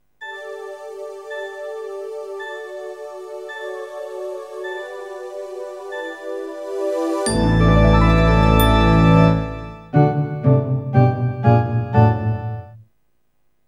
contains the theme's first five on-beat notes at the end.